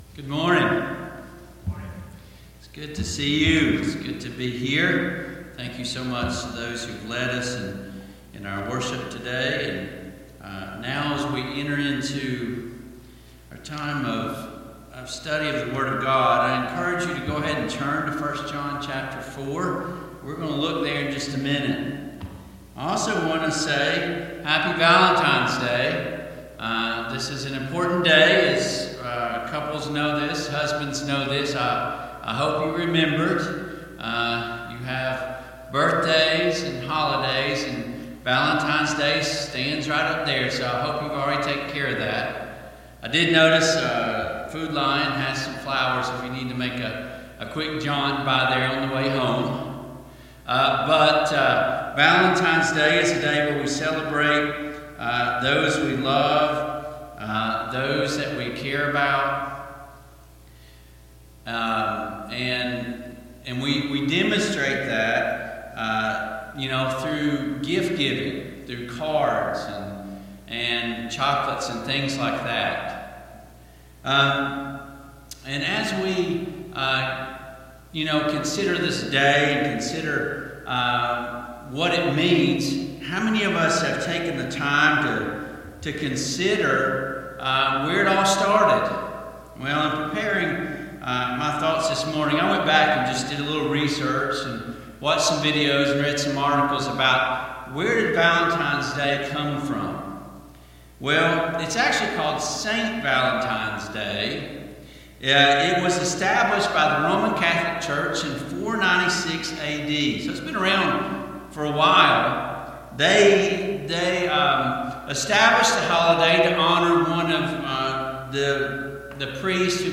Service Type: AM Worship Topics: Forgiveness , The Scheme of Redemption